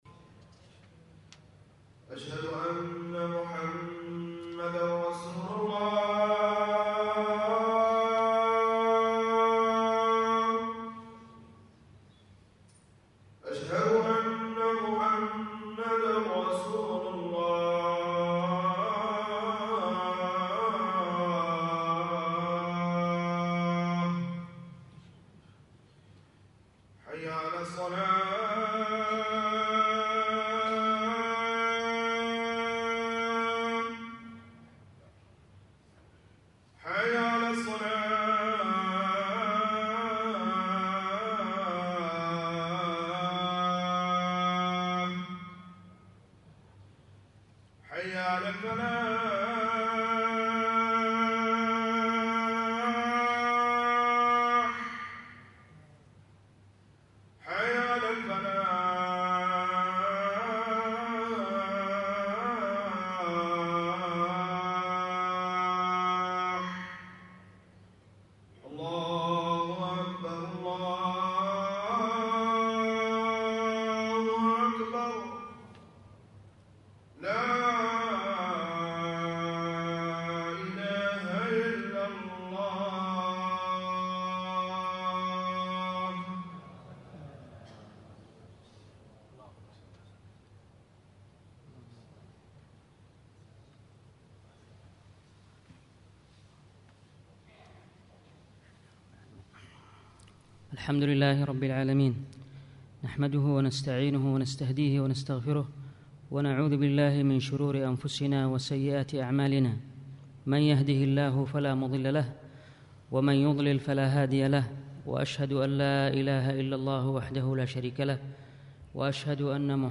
الخطبه
خطب الجمعة